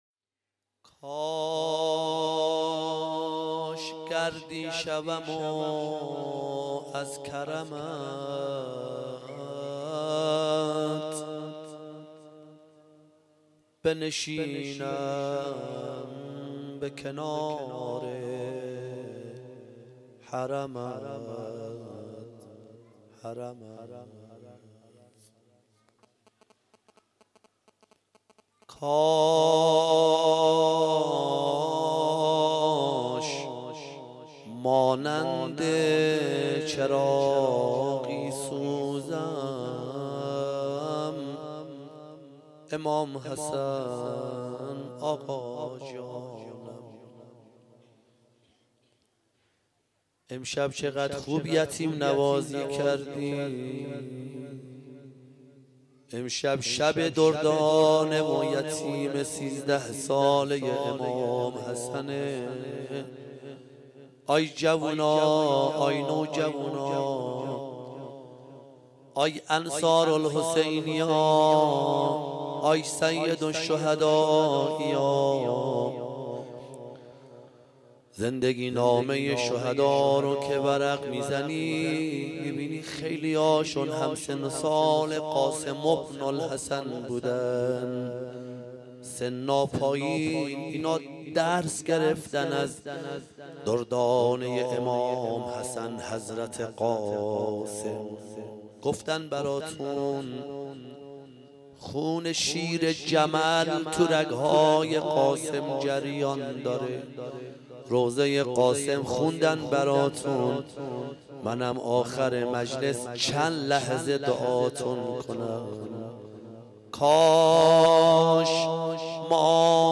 مجمع انصار الحسین(ع) نیشابور
شب ششم محرم 1395